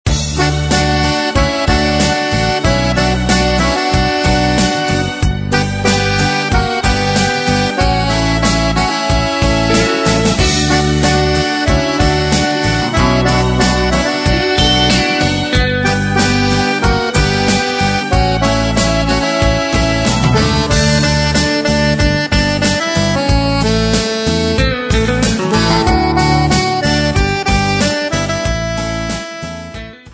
Besetzung: Akkordeon